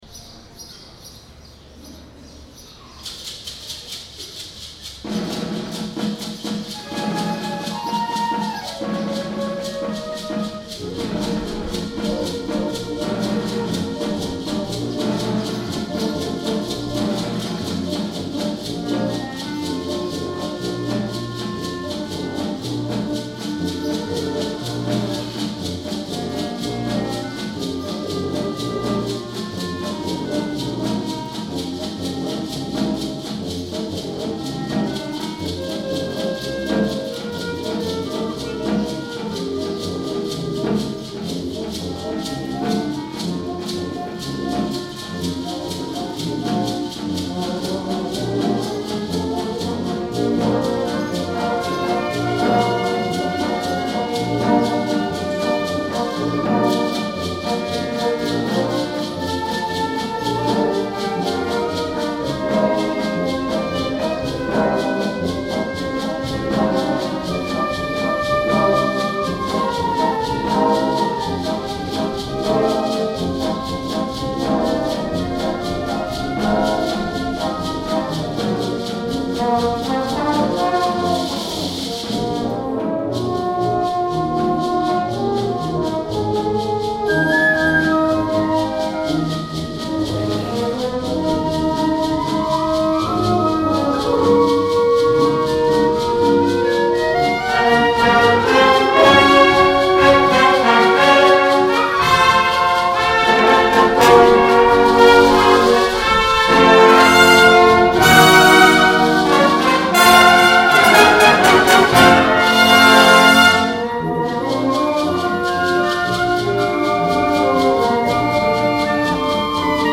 Concert Band
Recorded at the Prince William County Band Festival – May 22, 2022